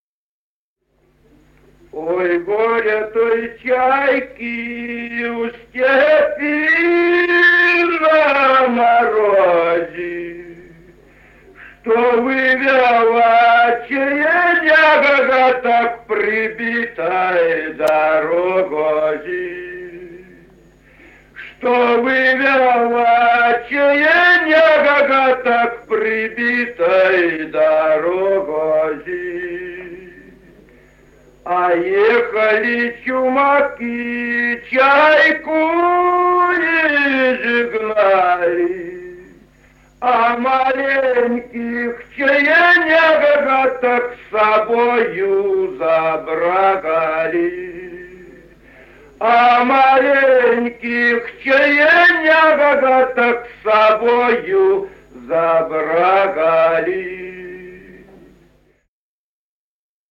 Песни села Остроглядово. Ой, горе той чайке И 0443-03